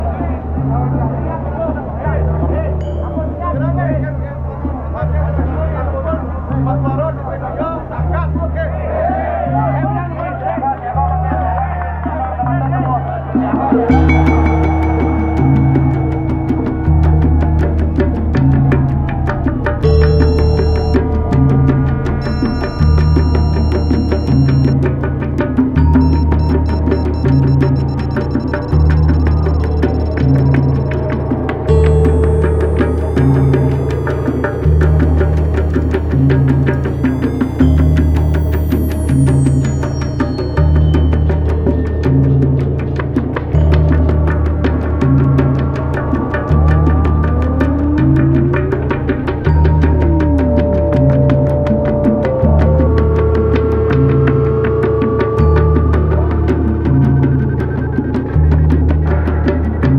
バレアリックやコズミック系DJも要チェックな個性的なサウンドがギッシリ！